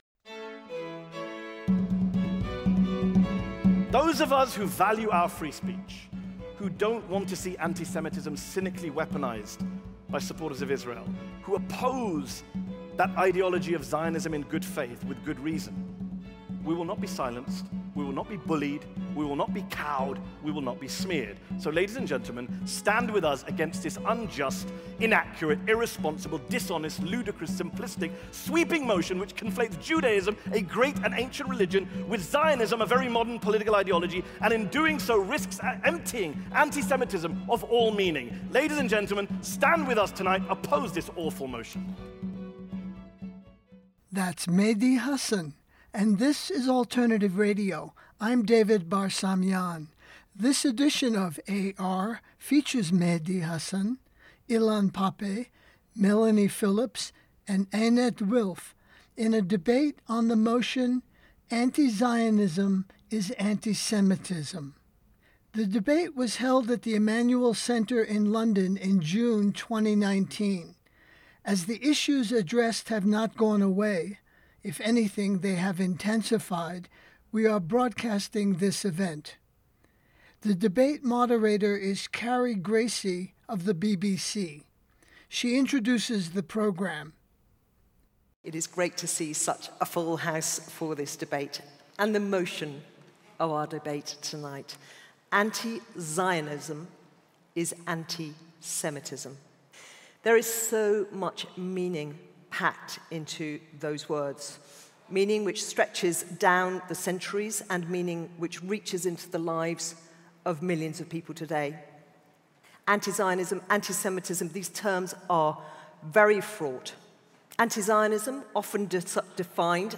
Mehdi Hasan, et al. – Anti-Zionism is Anti-Semitism [Debate]